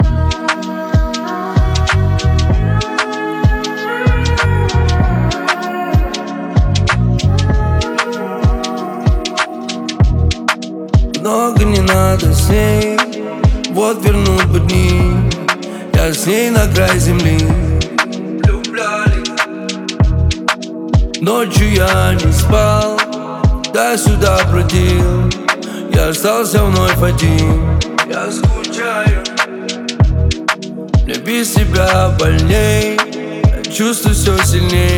Hip-Hop Hip-Hop Rap